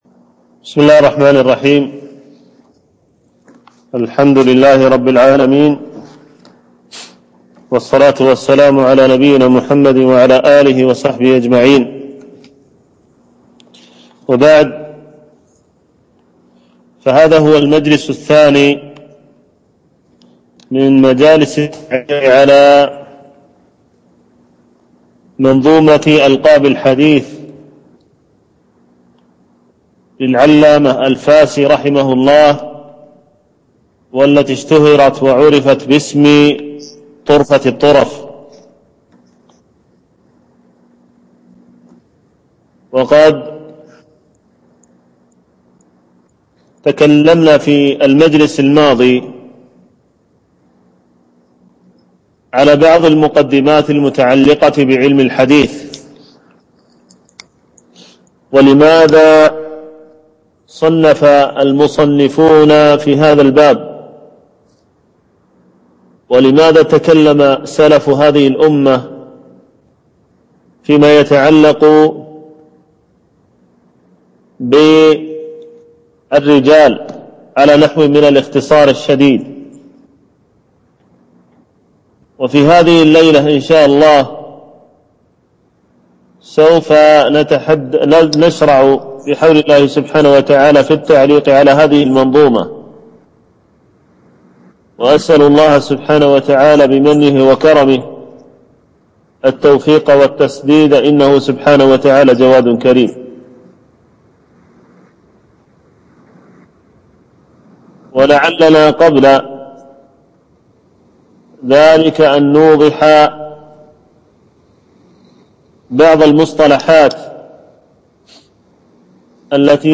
شرح طرفة الطرف في مصطلح من السلف - الدرس الثاني
طرفة الطرف في مصطلح من سلف - الدرس الثاني.mp3